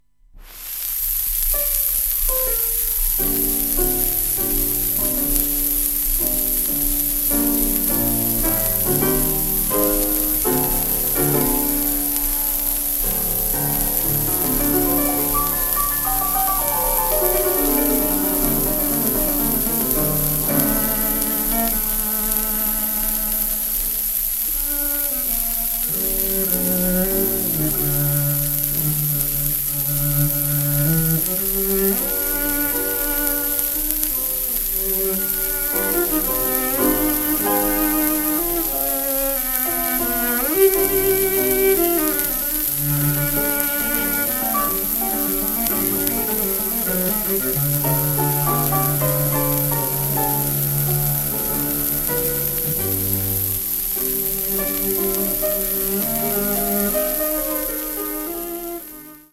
(1942年5月東京録音)